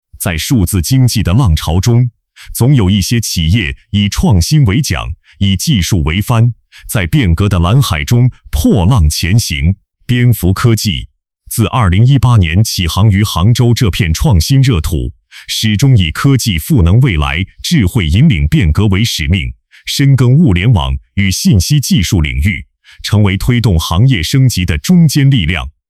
🎙 AI配音示例
宣传片-男